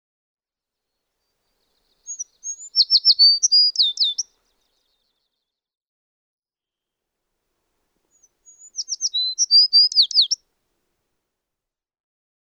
American tree sparrow
♫279—one song from each of two individuals
279_American_Tree_Sparrow.mp3